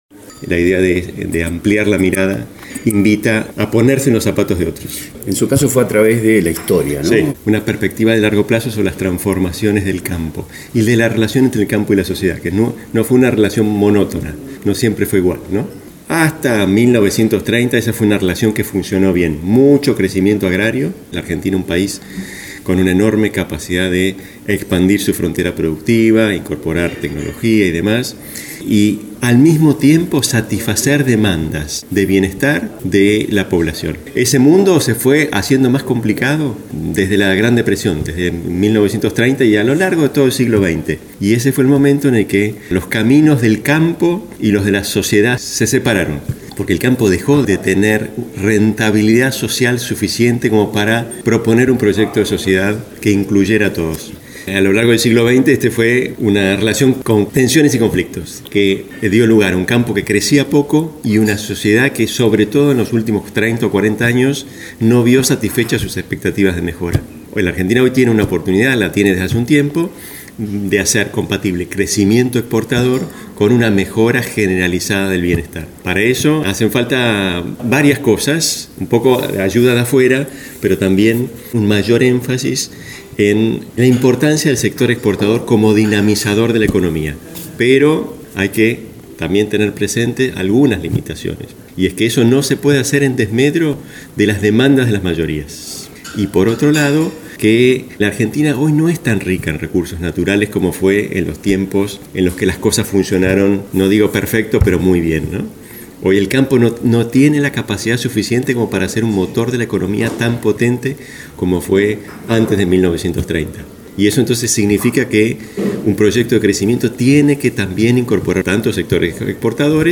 Audio. Entrevista Roy Hora